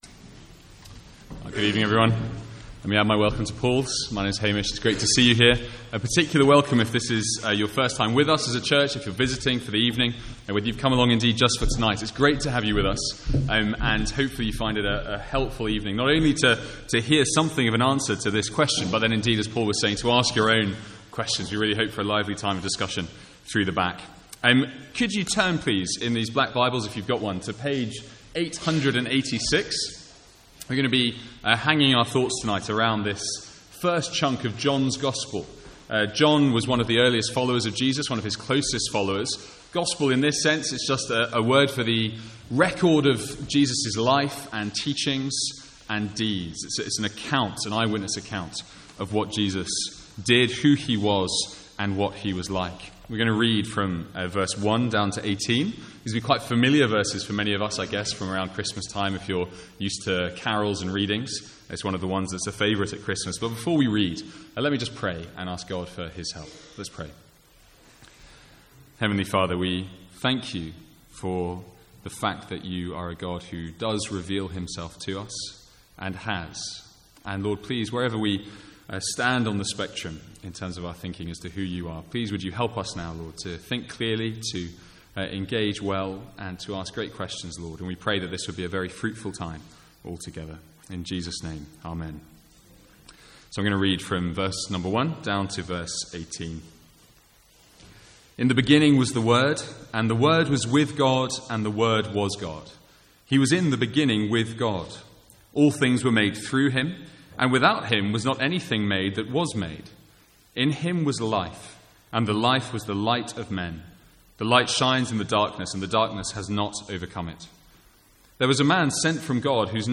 Sermons | St Andrews Free Church
From the guest service on 8th November 2015.